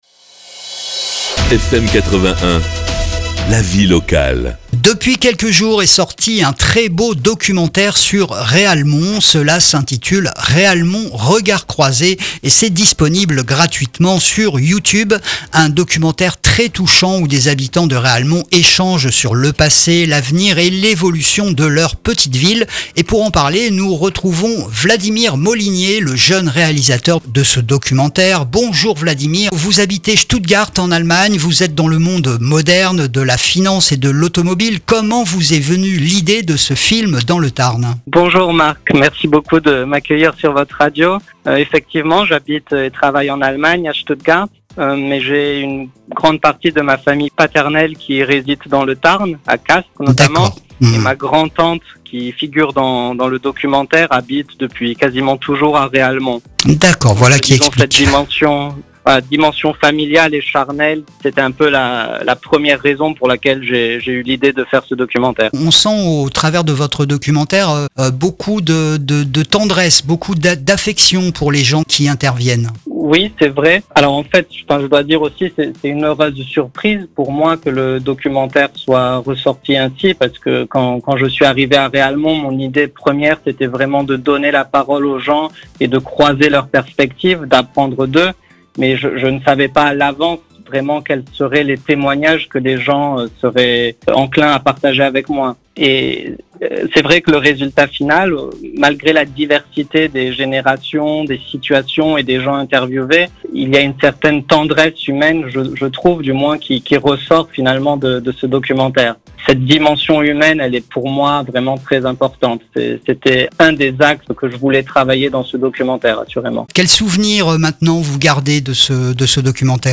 Un reportage plein de tendresse !